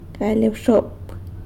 galy abxop[gàally ahbxo’p]